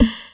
SNARE 18  -R.wav